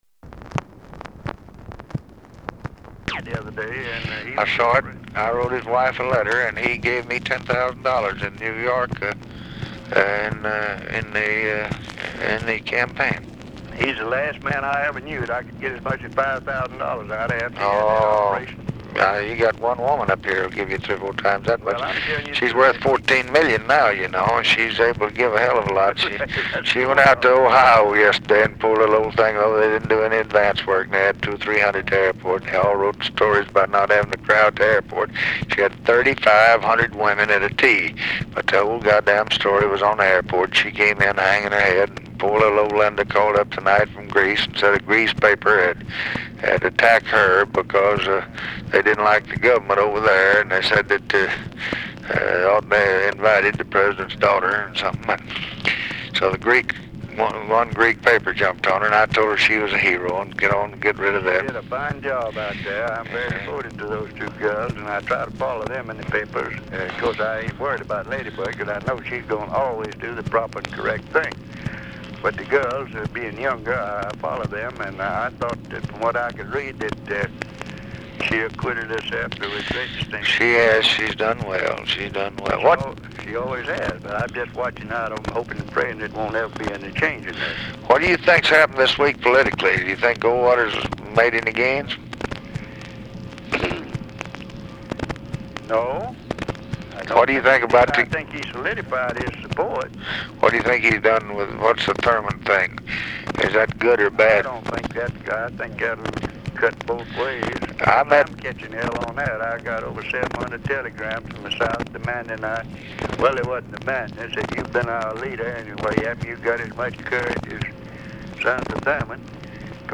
Conversation with RICHARD RUSSELL, September 18, 1964
Secret White House Tapes